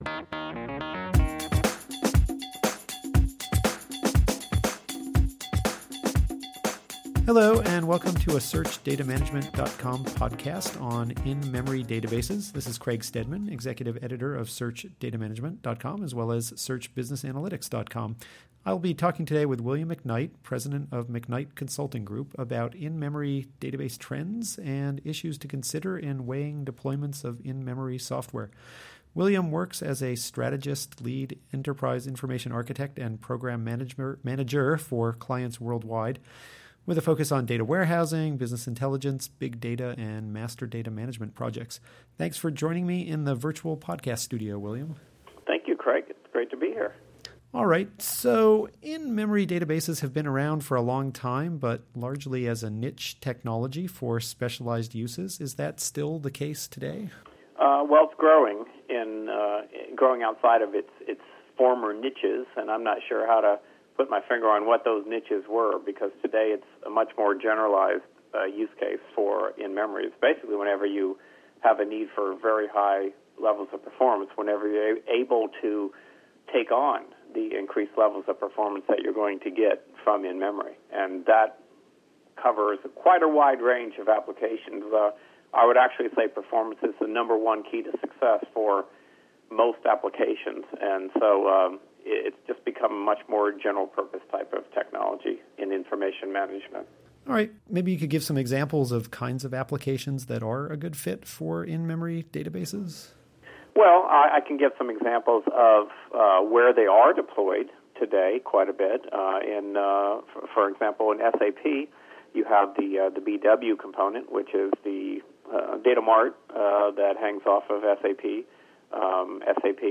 In this podcast Q&A, consultant